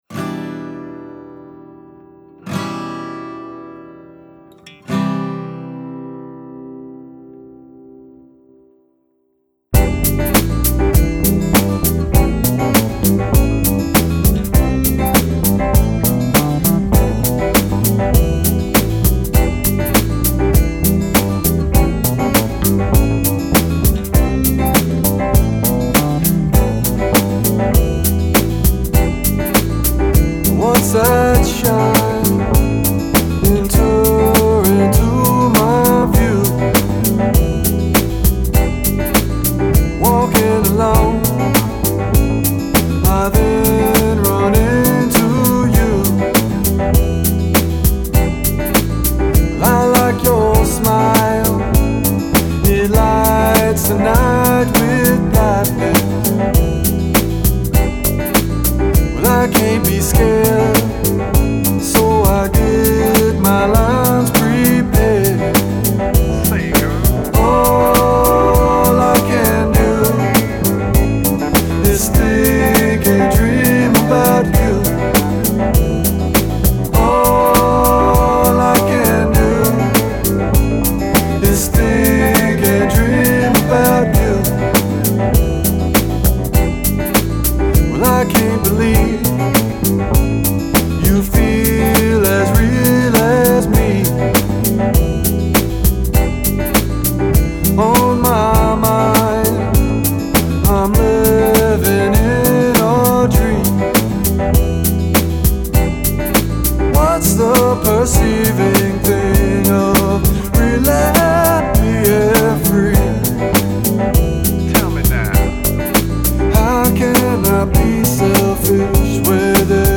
Solo Project.